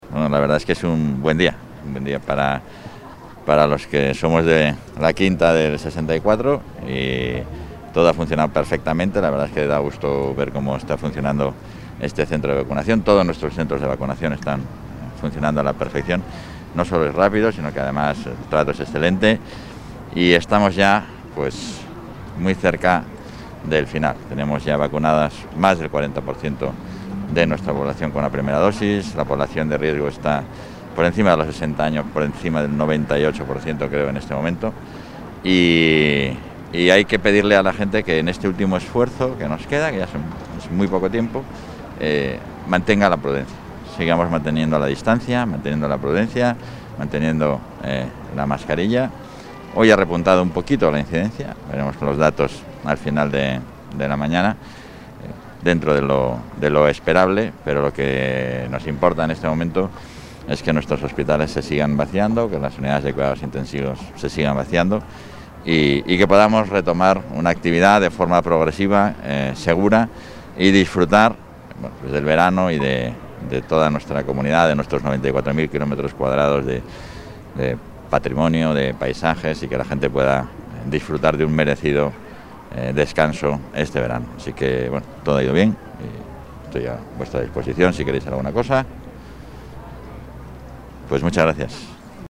Declaraciones del vicepresidente de la Junta.